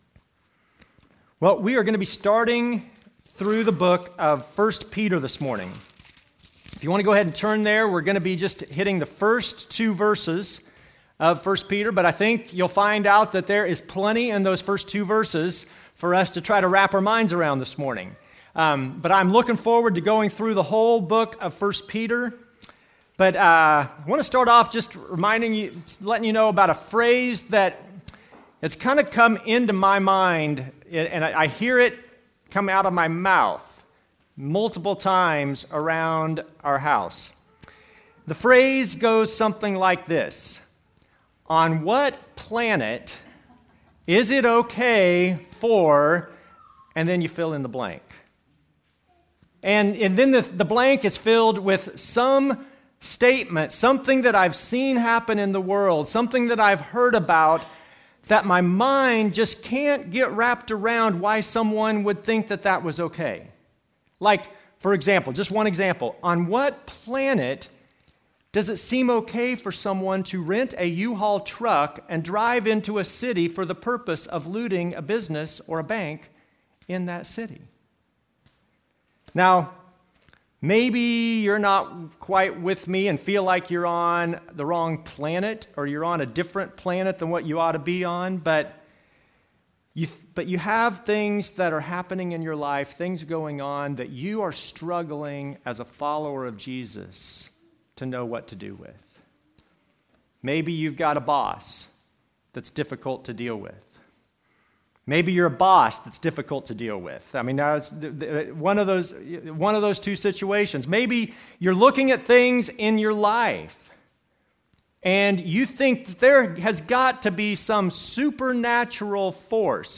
Passage: 1 Peter 1:1, 2 Service Type: Normal service